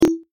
Enchanted_stereo_audio-volume-change.ogg